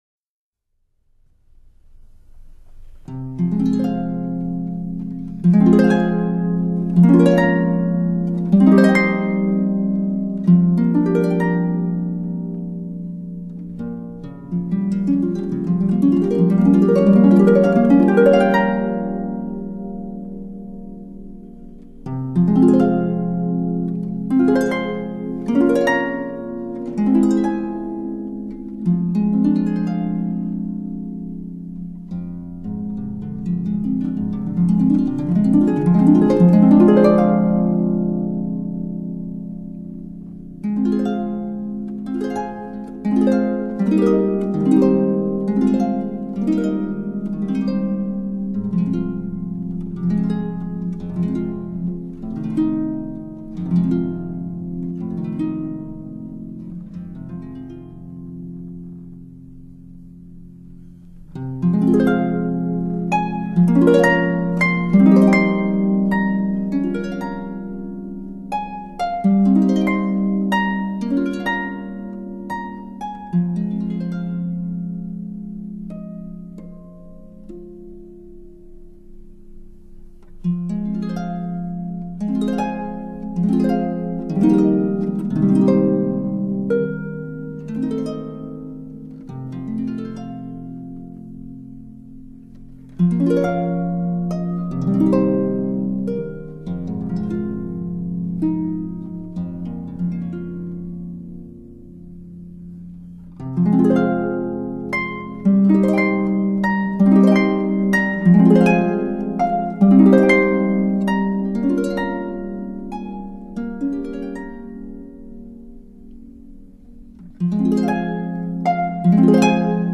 Chinese traditional